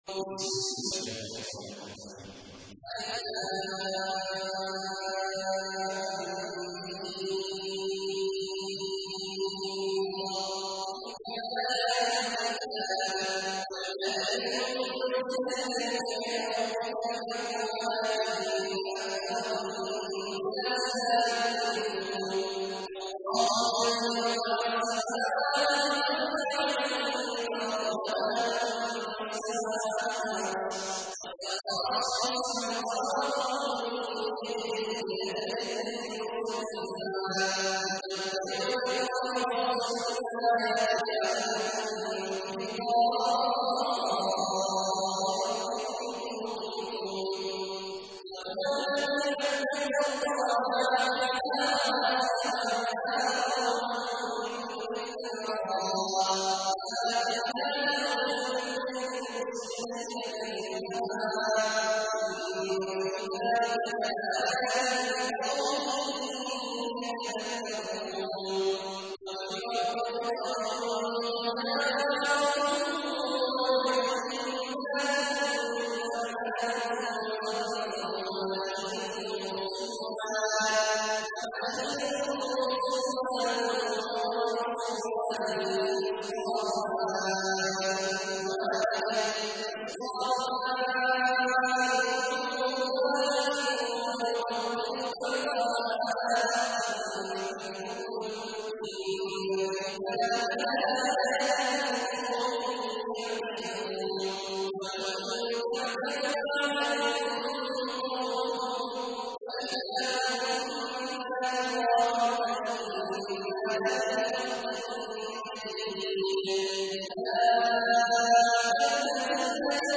تحميل : 13. سورة الرعد / القارئ عبد الله عواد الجهني / القرآن الكريم / موقع يا حسين